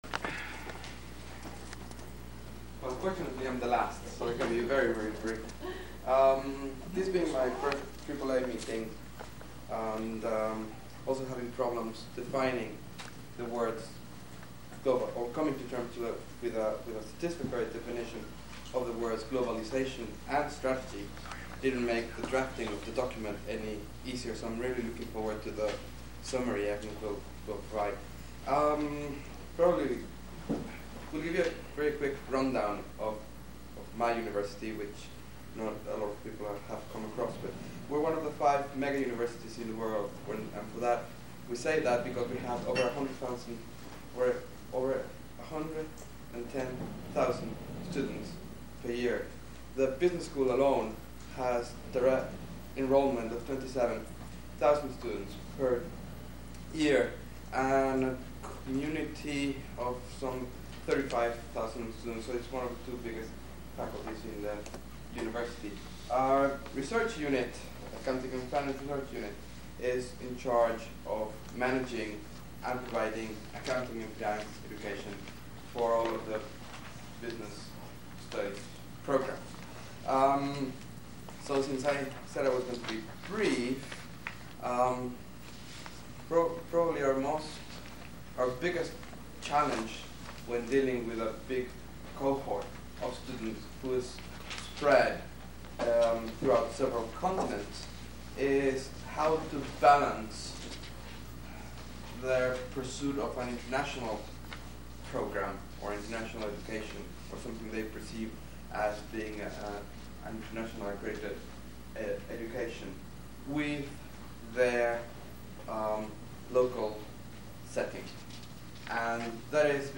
The GSAR speakers in Berlin were not wearing microphones, so the only audio captured was through the microphone built into my old Sony camcorder.